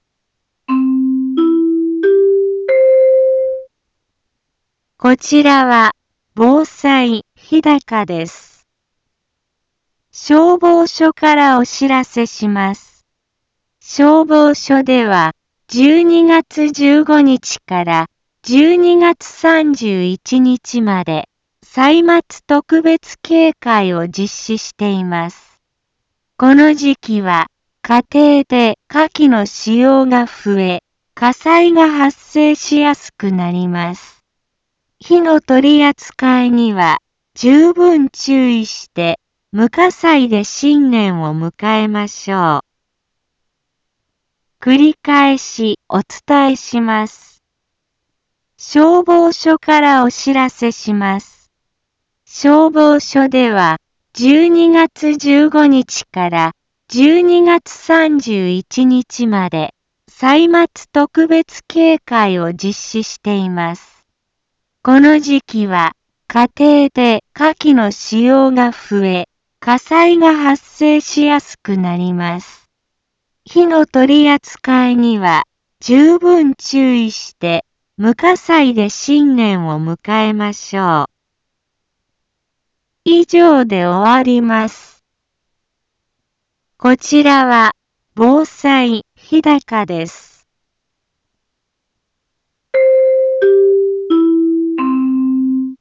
一般放送情報
BO-SAI navi Back Home 一般放送情報 音声放送 再生 一般放送情報 登録日時：2023-12-15 15:03:23 タイトル：歳末特別警戒について インフォメーション： 消防署からお知らせします。 消防署では、12月15日から12月31日まで、歳末特別警戒を実施しています。